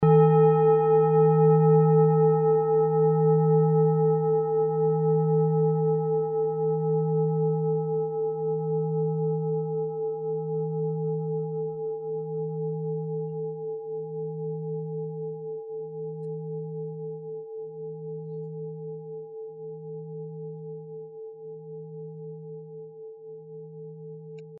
Klangschale Orissa Nr.26
Klangschale-Gewicht: 830g
Klangschale-Durchmesser: 17,9cm
Die Klangschale kommt aus einer Schmiede in Orissa (Nordindien). Sie ist neu und wurde gezielt nach altem 7-Metalle-Rezept in Handarbeit gezogen und gehämmert.
Wasserstoffgamma Frequenz
klangschale-orissa-26.mp3